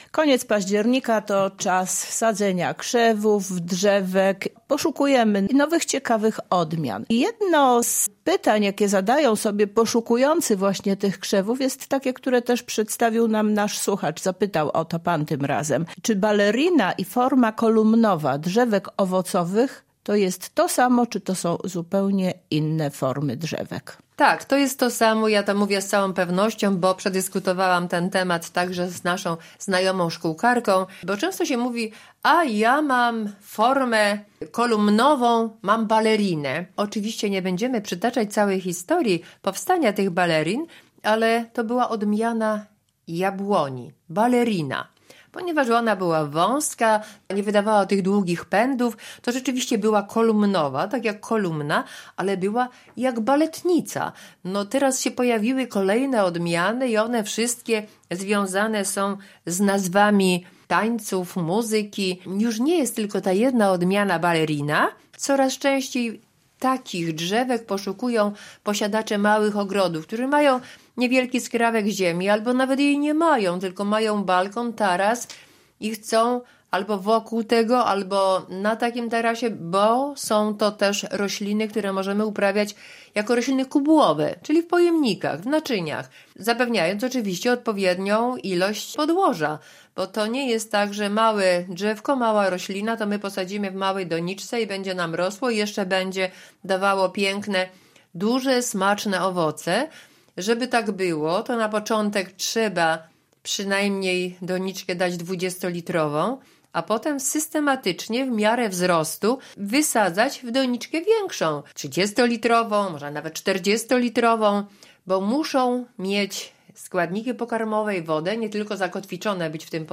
rozmowie